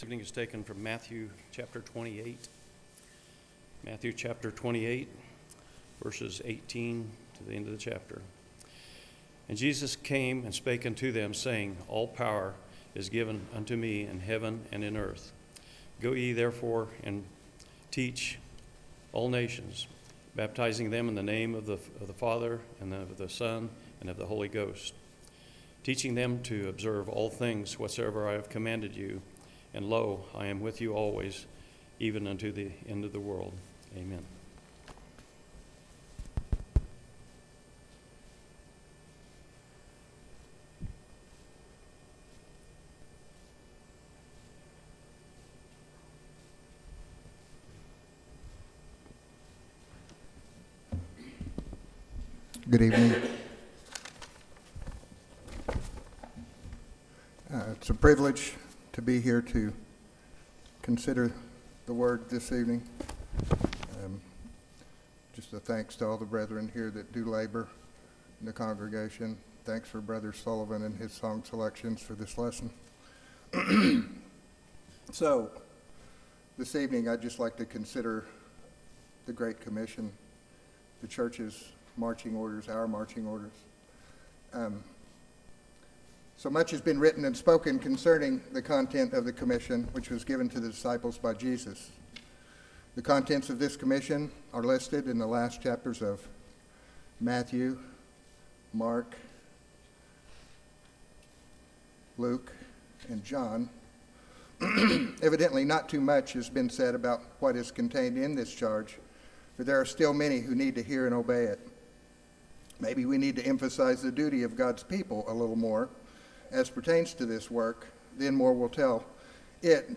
Matthew 28:18-20 Service Type: Sunday PM Topics